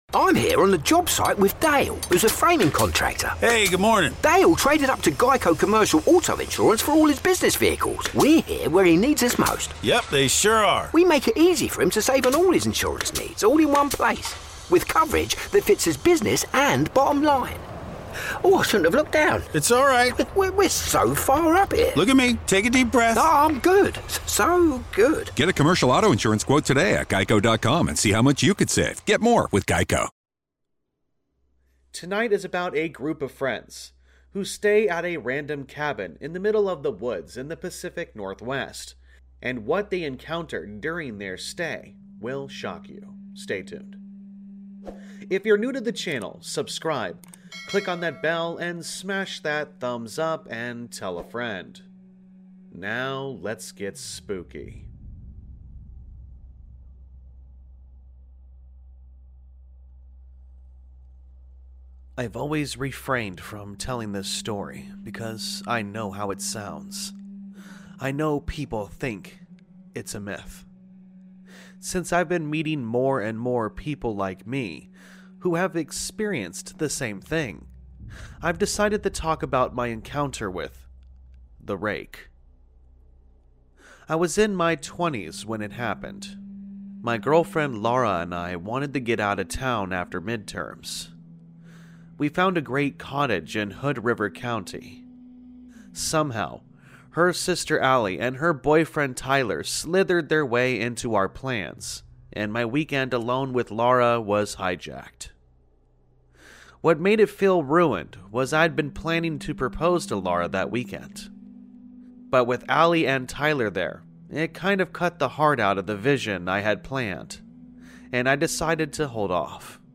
All Stories are read with full permission from the authors